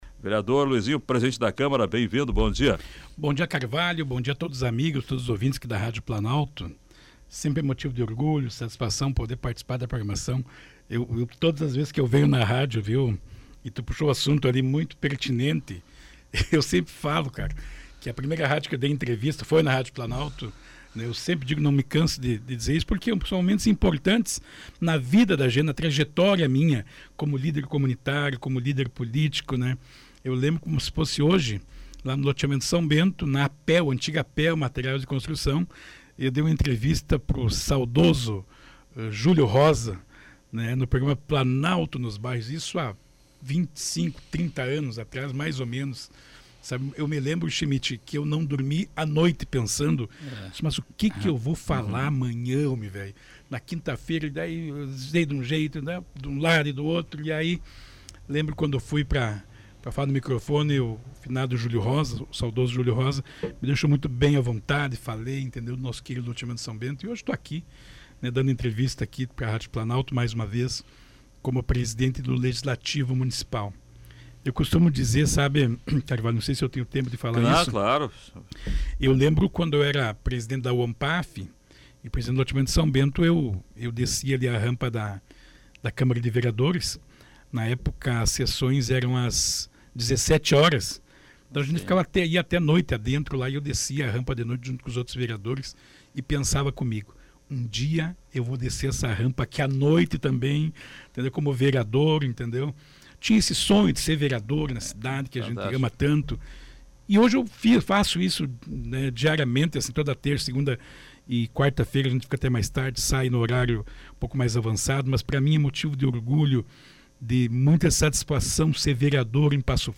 O programa Comando Popular, da Rádio Planalto News (92.1), recebeu na manhã de quarta-feira, 01, o vereador Luizinho Valendorf (PSDB), presidente da Câmara de Passo Fundo.